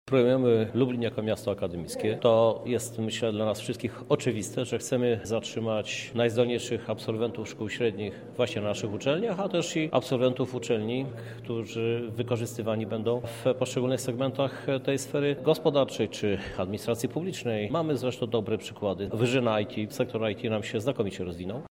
Najzdolniejsi studenci wyróżnieni przez Ratusz. Znamy laureatów Miejskiego Programu Stypendialnego – mówi Krzysztof Żuk, prezydent Lublina